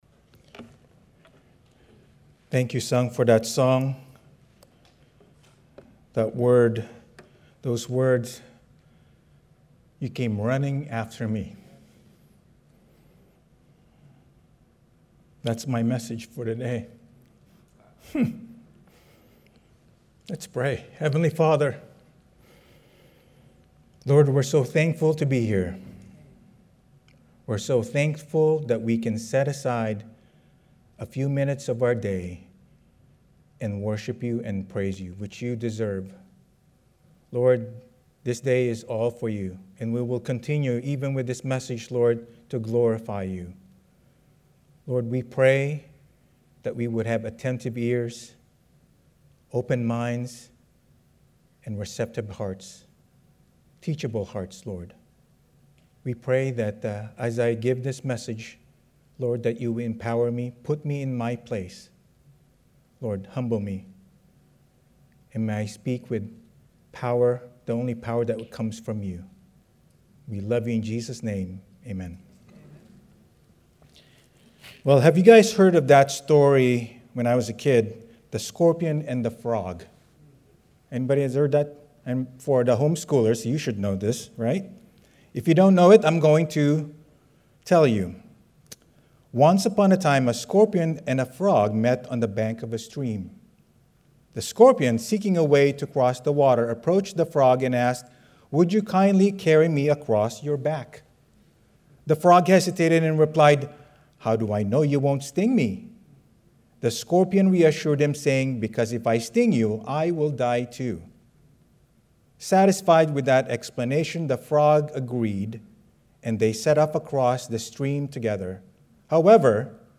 Sermon 11/16/2025 Slow Fade: The Story of Lot